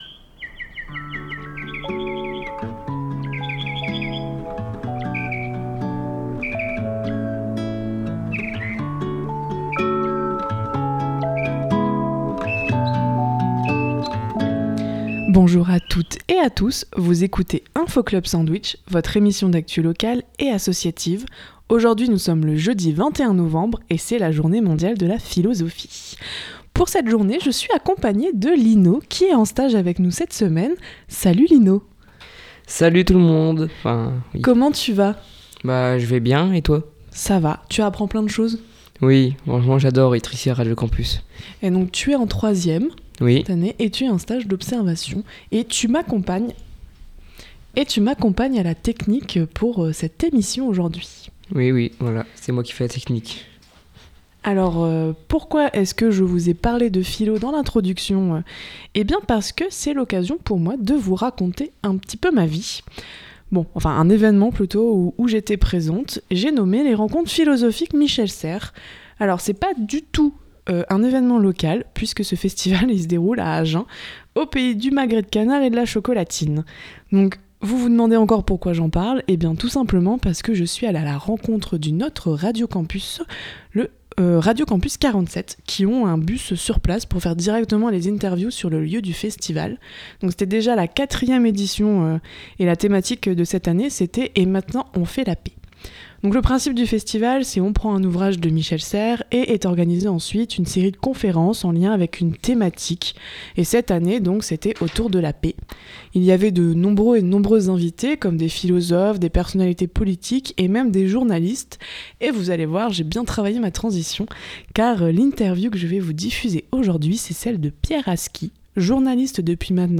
On était invité par Radio Campus 47 et on vous propose de découvrir l’interview du journaliste Pierre Haski qui revient sur son parcours.